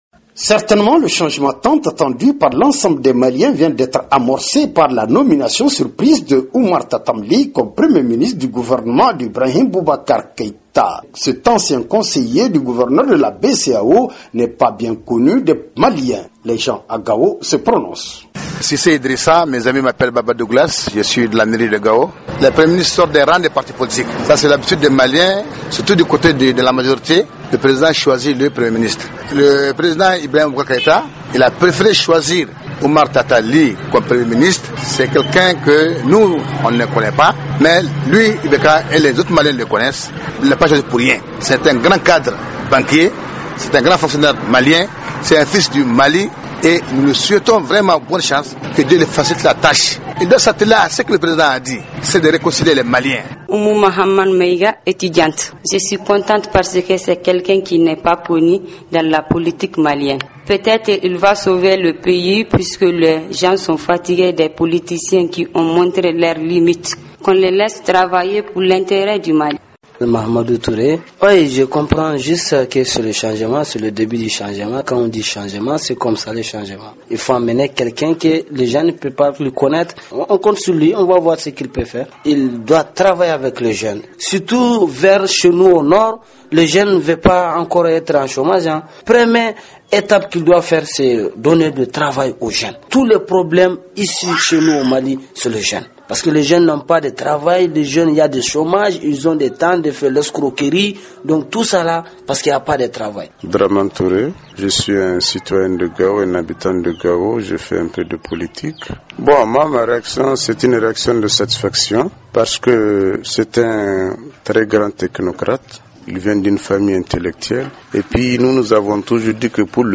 Le reportage de notre correspondant